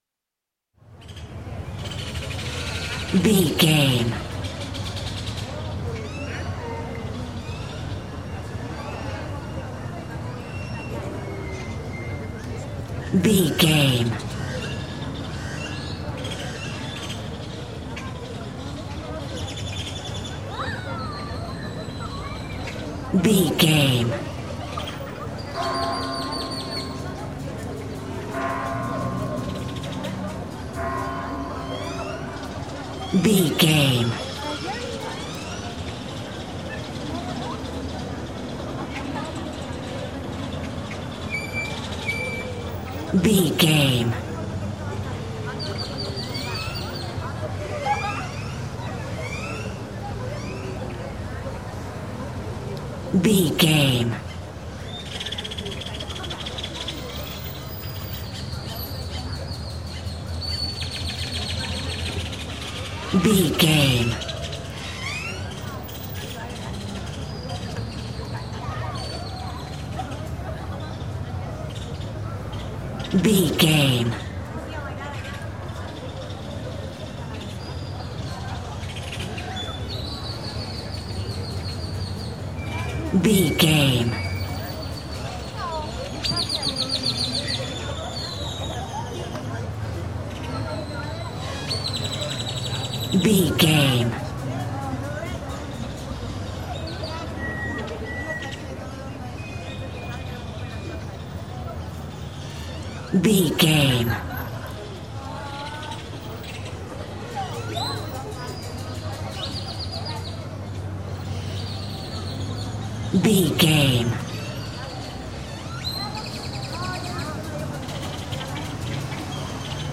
Mexico taxco street downtown
Sound Effects
urban
chaotic
ambience